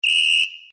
• Taster 2 > Abfahrtspfiff der Aufsicht (Trillerpfeife)